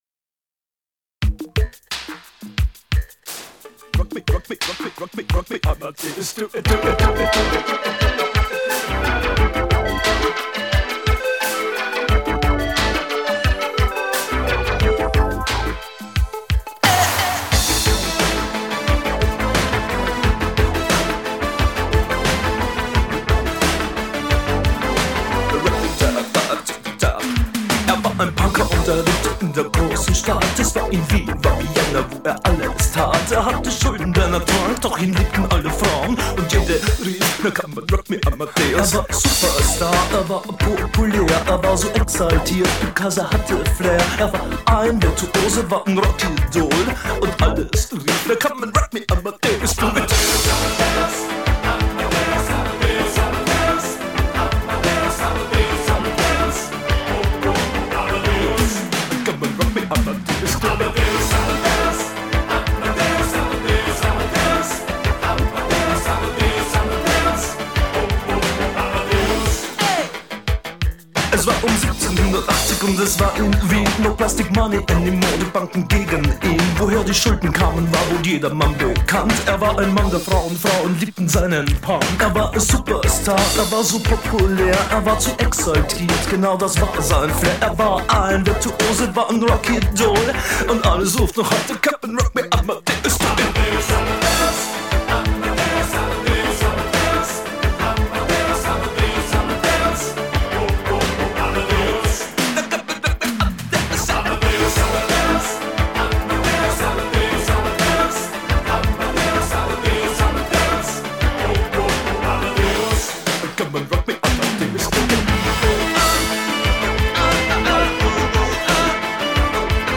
Перетерплю це під старий добрий рок!